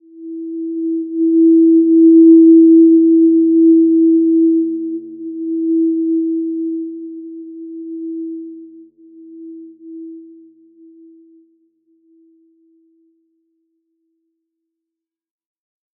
Simple-Glow-E4-mf.wav